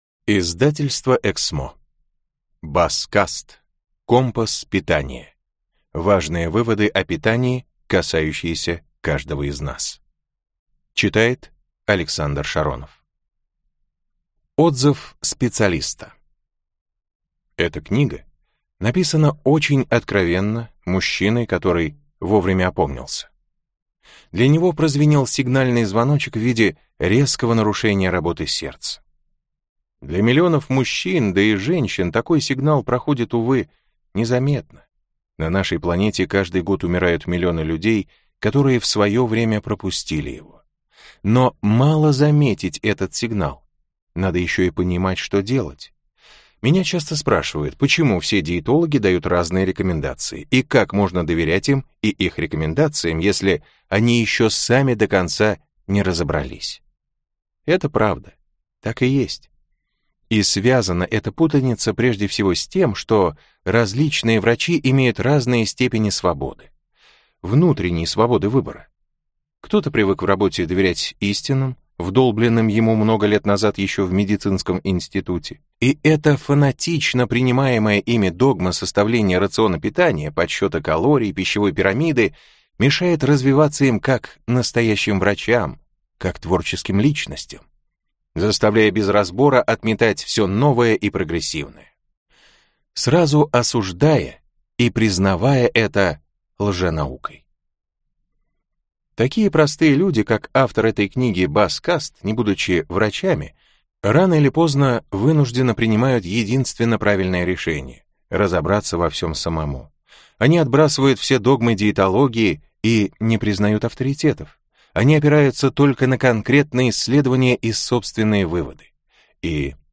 Аудиокнига Компас питания. Важные выводы о питании, касающиеся каждого из нас | Библиотека аудиокниг